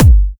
drum-hitnormal2.wav